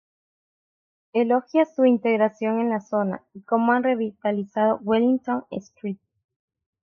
in‧te‧gra‧ción
/inteɡɾaˈθjon/